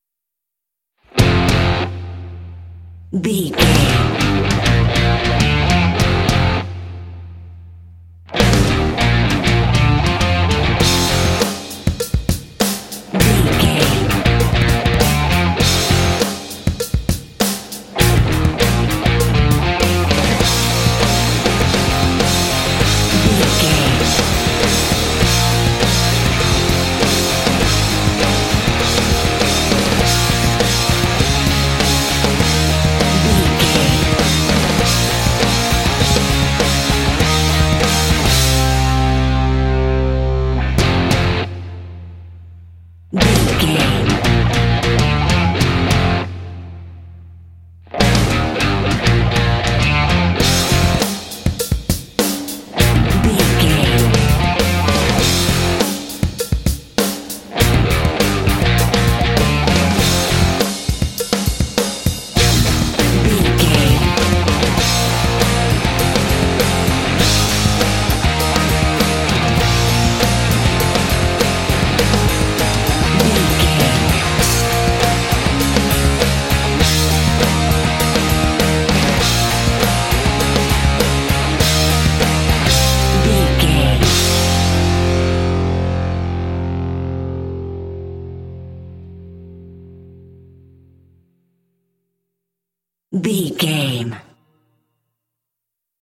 Epic / Action
Fast paced
Aeolian/Minor
driving
heavy
groovy
energetic
drums
electric guitar
bass guitar
rock
heavy metal
classic rock